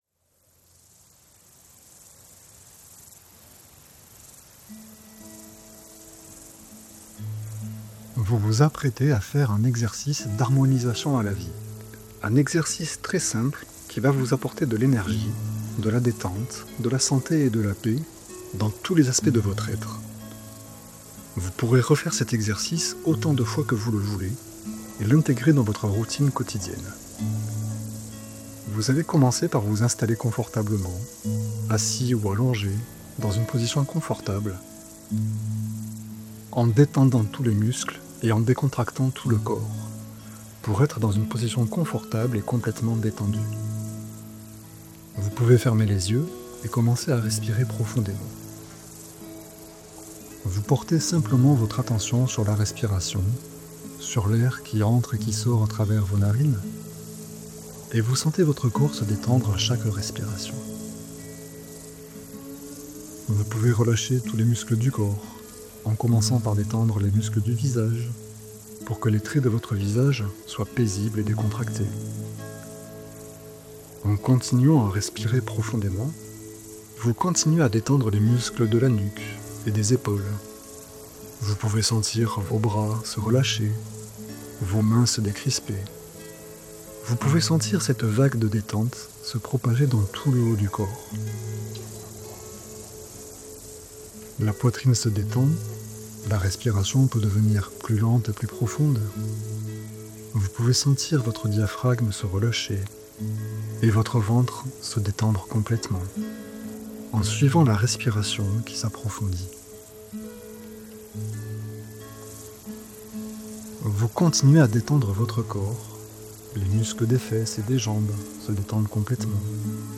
Voici une m�ditation guid�e pour les personnes ayant du mal � m�diter, et pour les personnes malades ou souffrantes. Elle vise � faire circuler l'�nergie de vie pour harmoniser les corps �nerg�tiques et physique, pour retrouver une bonne �nergie vitale, des �motions apais�es et lumineuses, et un mental calme et clair.
Cette m�ditation est accompagn�e de sons binauraux, qui aident le cerveau � se mettre en �tat de m�ditation, aussi il est pr�f�rable de l'�couter avec un casque audio. La musique et les fr�quences sont bas�es sur la fr�quence 432 Hz pour aider � l'harmonisation.